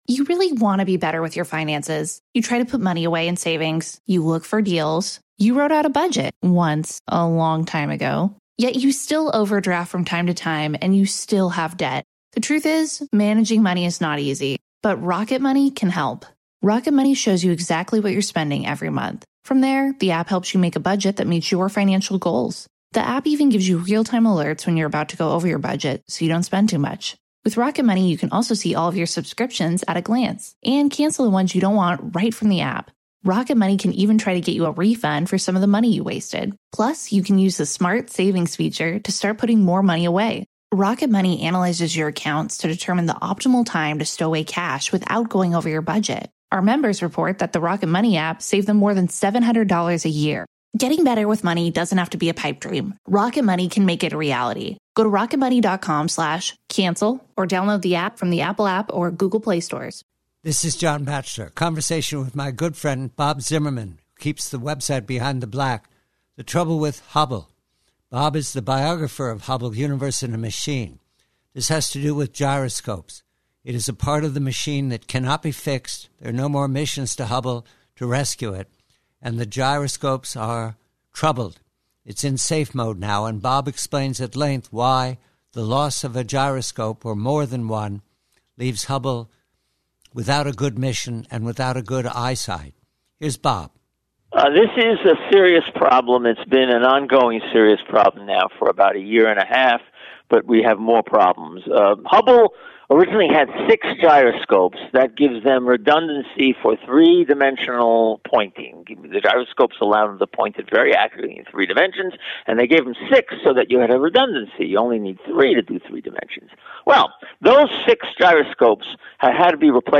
PREVIEW: From a longer conversation on space engineering